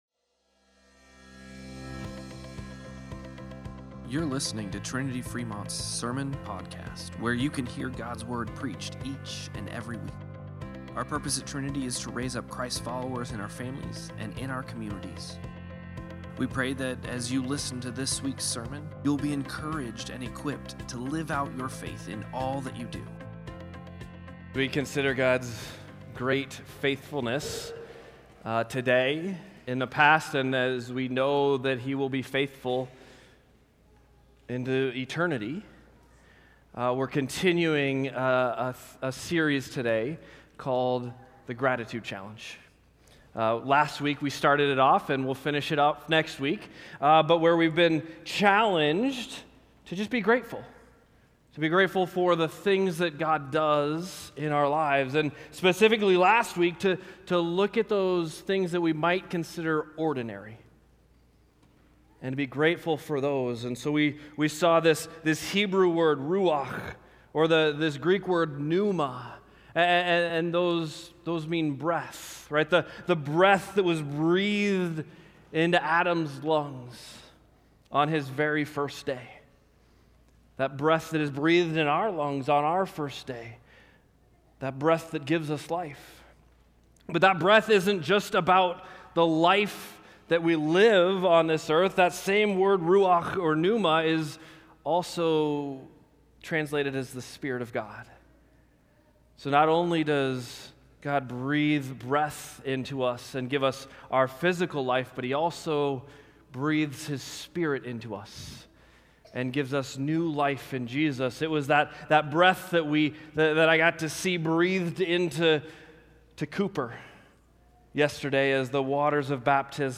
Sermon-Podcast-11-17.mp3